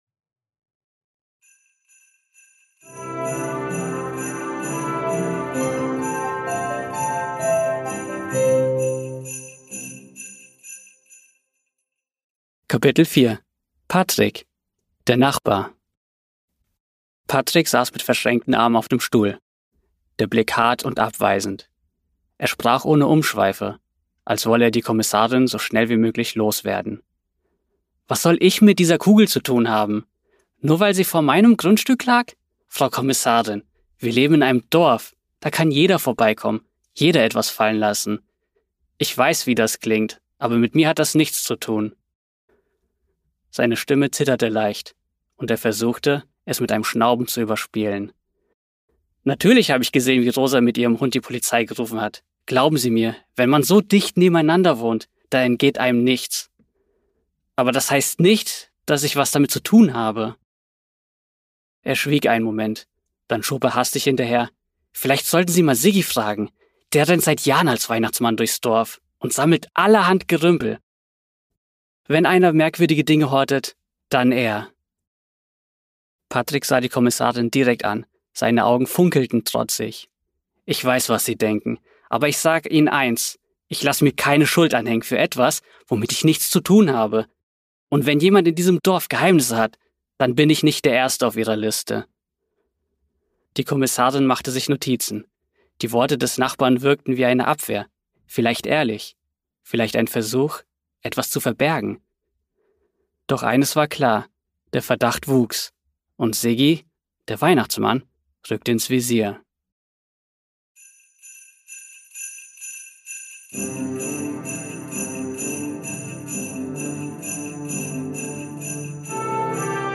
Kriminalgeschichte. Lass dich von acht verzaubernden Stimmen in die